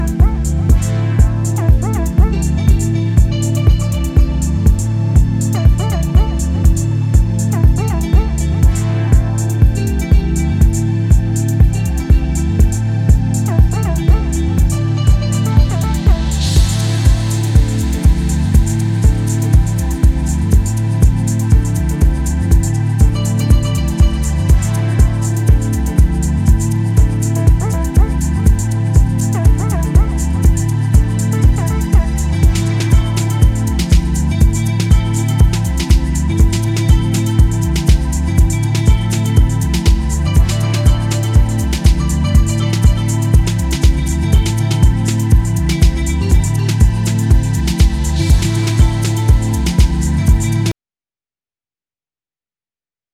秀逸なディープ・ハウスをじっくり堪能できるおすすめ盤です！